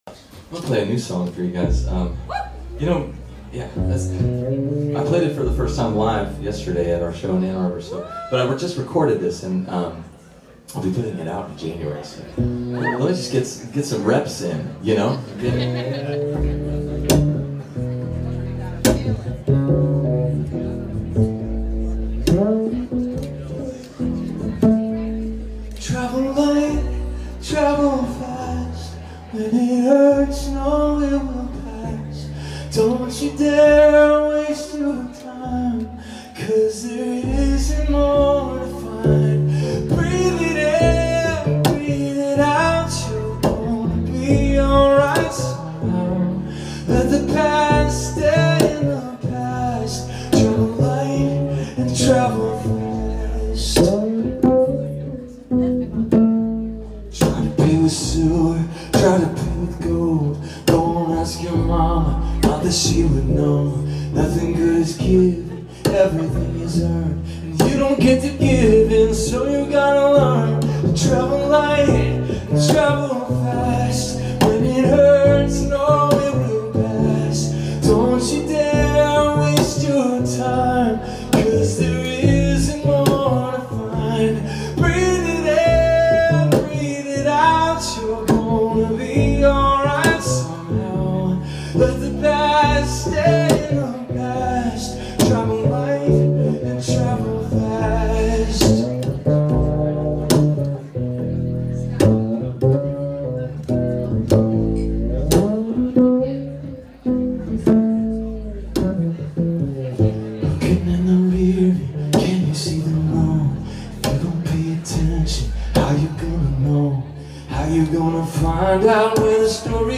Travel-Light-Travel-Fast-Live-in-Toronto.mp3